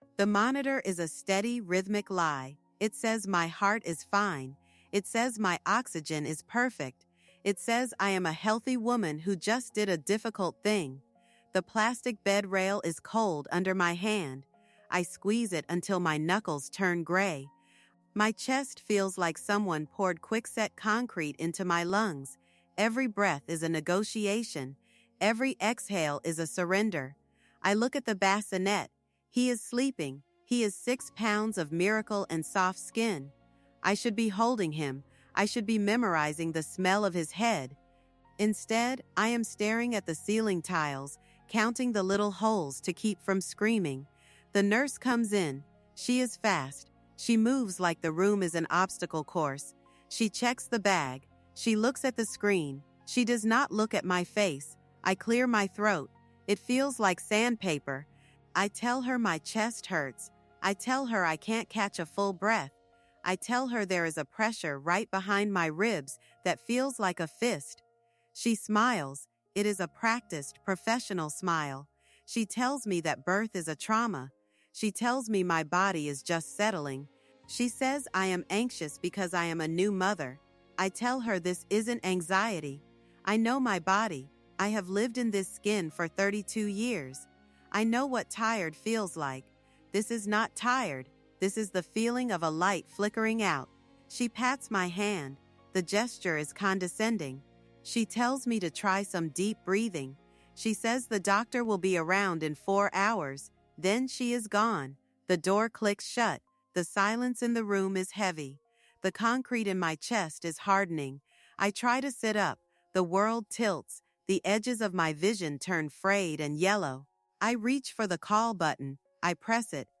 In this episode of THE TRIALS OF WOMAN, we explore the harrowing reality of the Black maternal mortality gap in the United States through a visceral, first-person narrative.